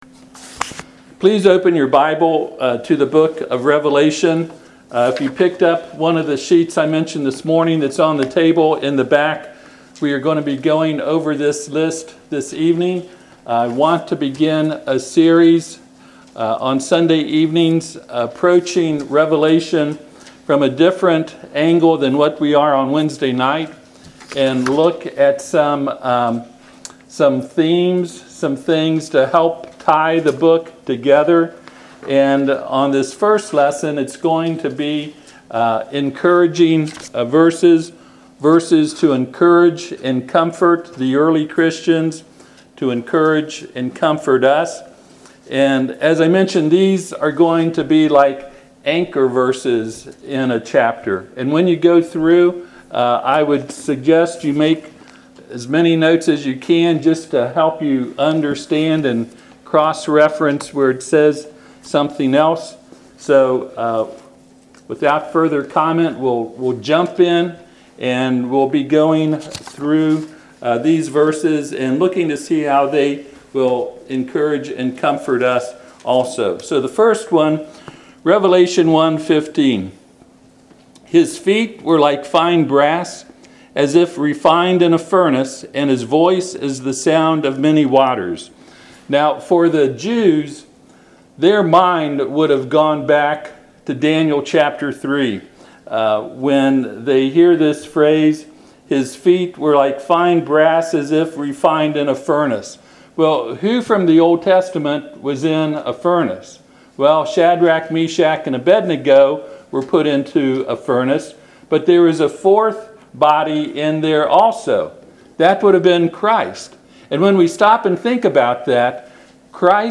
Revelation 1:15 Service Type: Sunday PM Topics